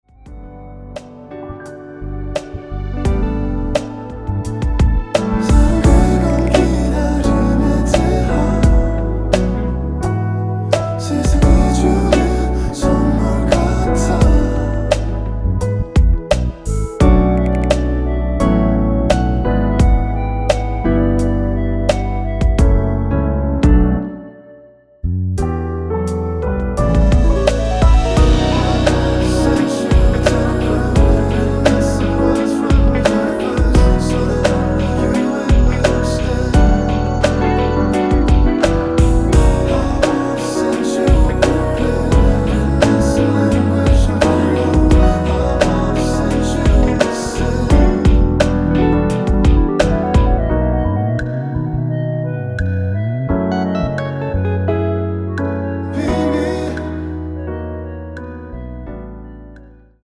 원키에서(-1)내린 멜로디와 코러스 포함된 MR입니다.(미리듣기 확인)
앞부분30초, 뒷부분30초씩 편집해서 올려 드리고 있습니다.
중간에 음이 끈어지고 다시 나오는 이유는
(멜로디 MR)은 가이드 멜로디가 포함된 MR 입니다.